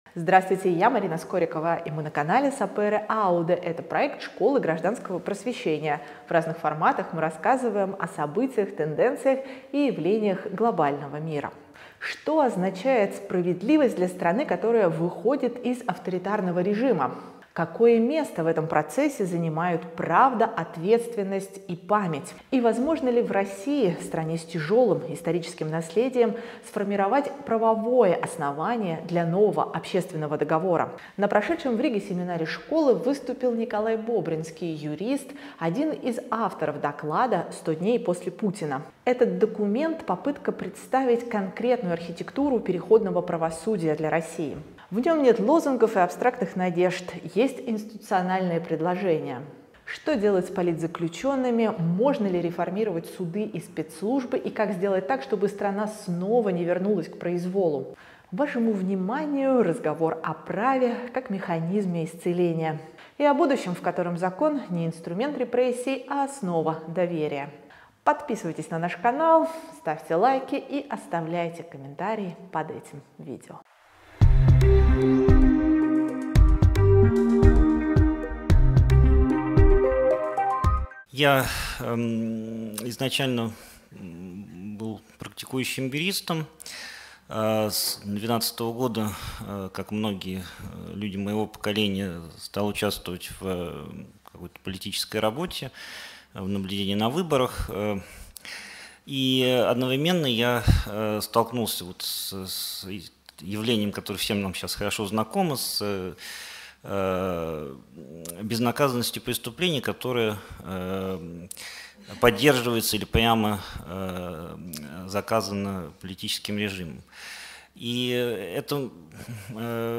Sapere Aude / Школа гражданского просвещения: Путь к справедливости. Дискуссия